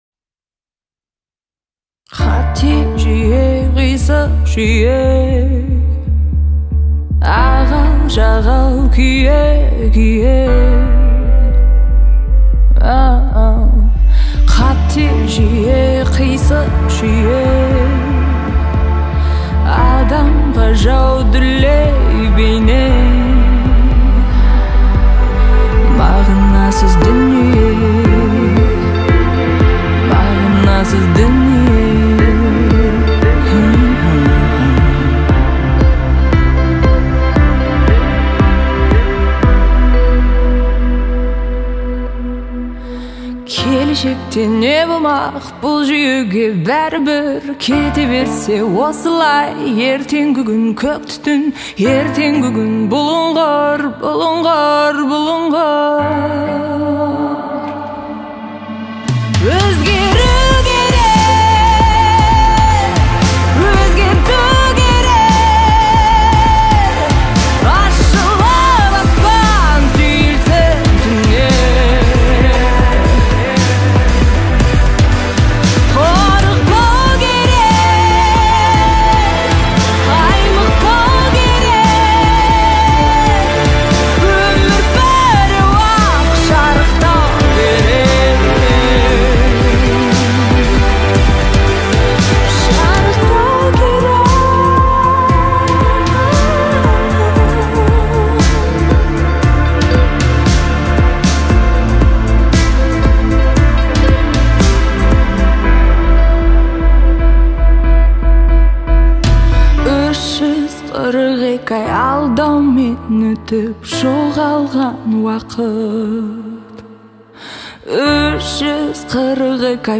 это трек в жанре поп с элементами этно